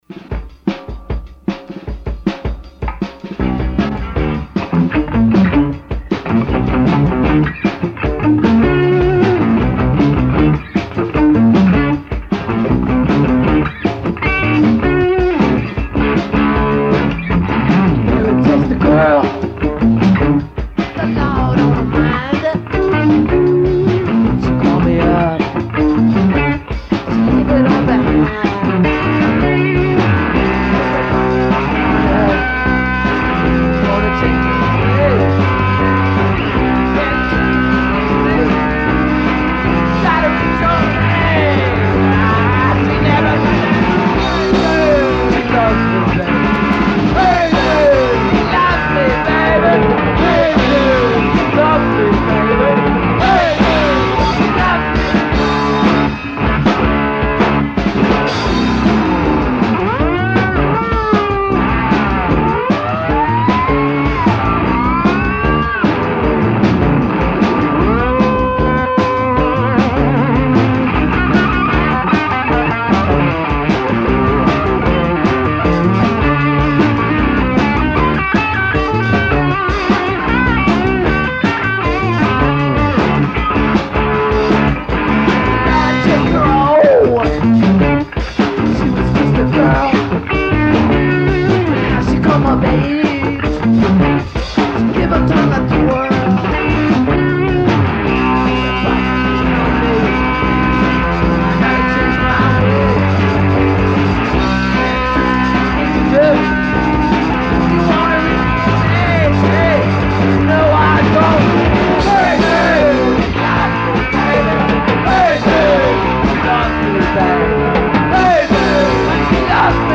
Rehearsals 5-12 + 17-1983